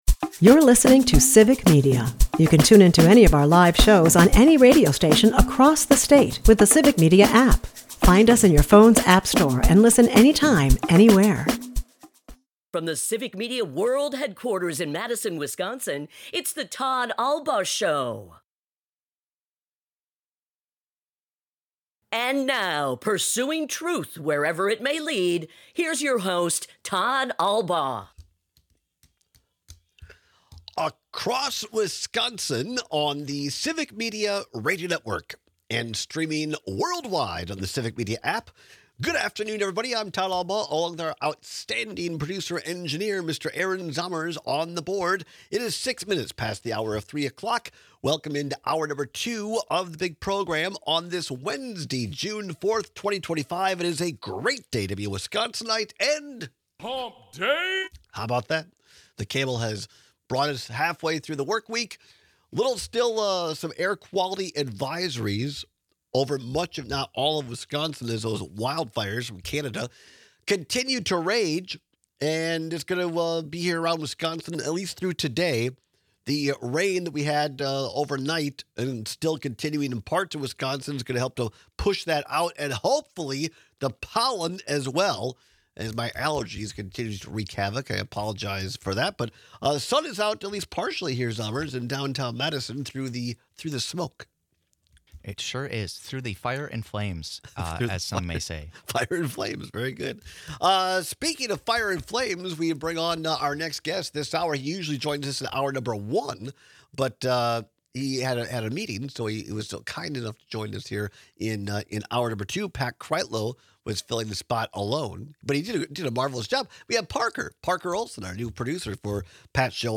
We take your calls and texts.